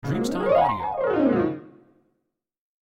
Il piano del fumetto riporta in scala su e giù il carillon